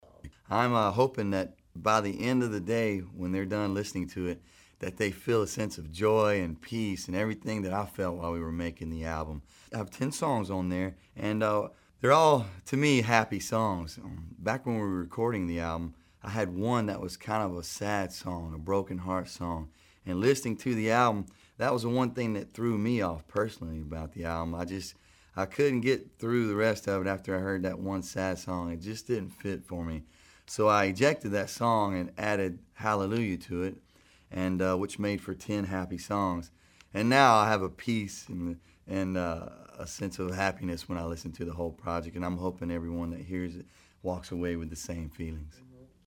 AUDIO: Billy Currington says he hopes fans will feel a sense of happiness when they listen to his new album, We Are Tonight.